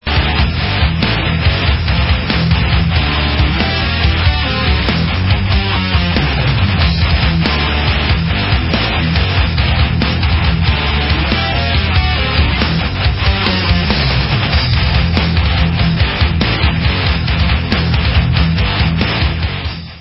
sledovat novinky v oddělení Rock/Progressive